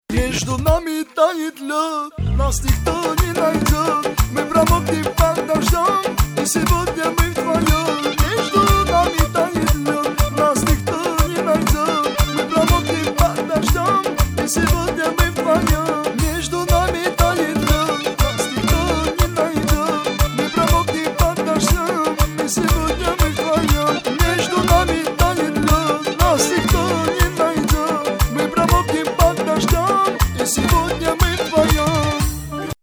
Категория: Смешные реалтоны